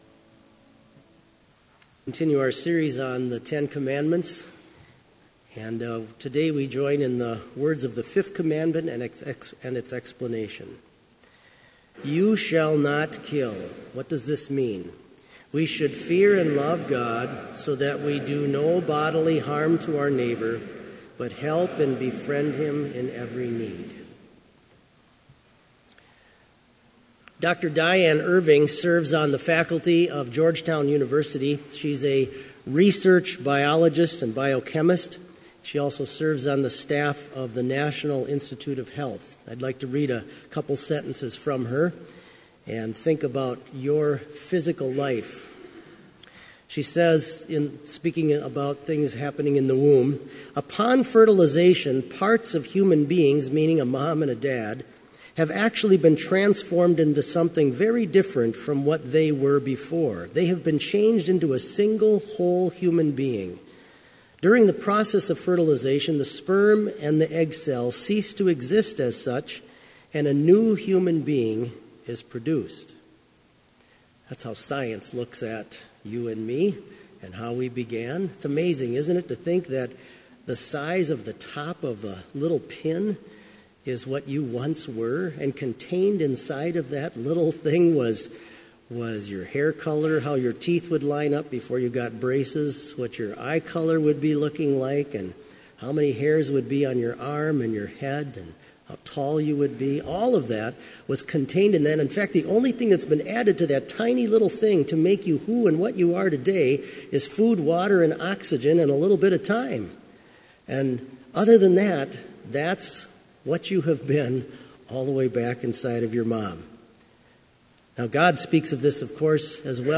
Complete service audio for Chapel - March 17, 2017